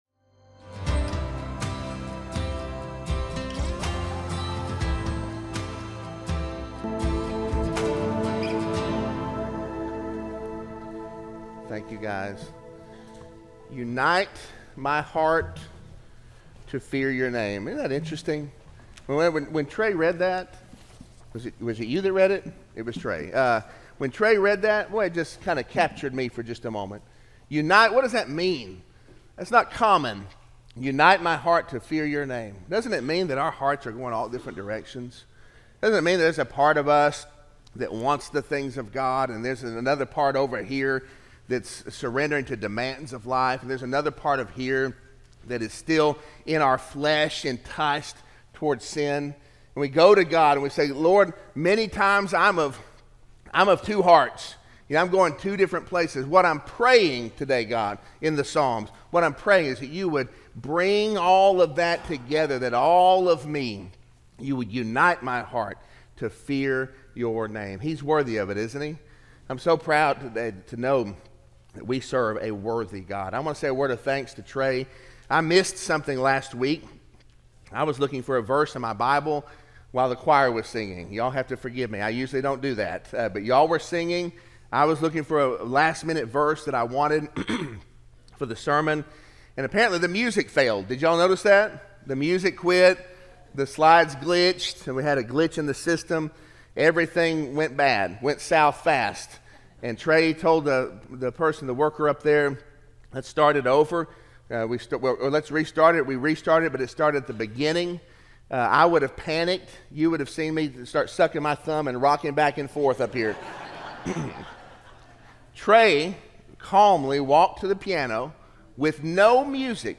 Sermon-6-16-24-audio-from-video.mp3